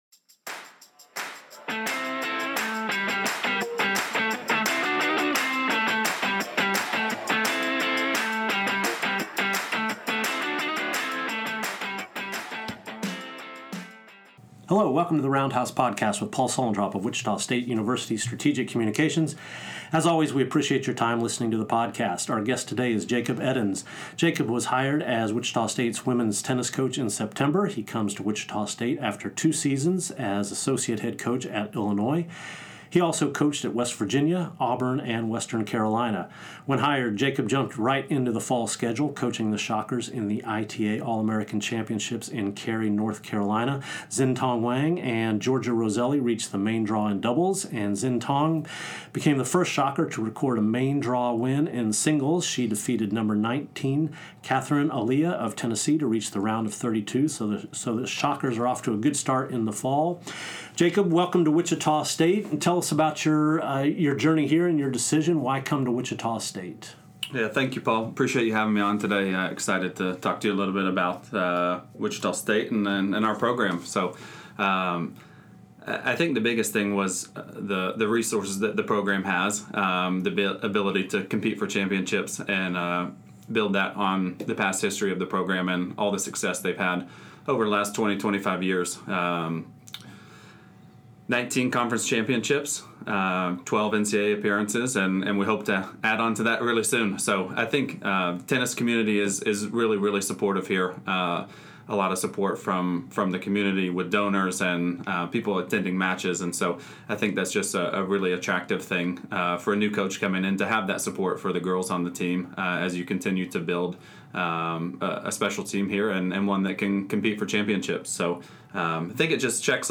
He joins the podcast to talk about his inside info on the Shocker job, courtesy of the coaching family he married into.